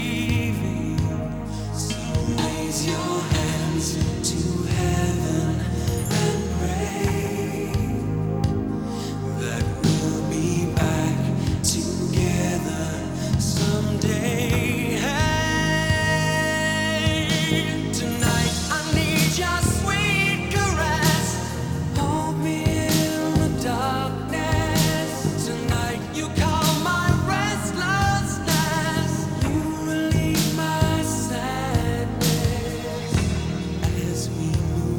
Rock Pop Adult Contemporary
Жанр: Поп музыка / Рок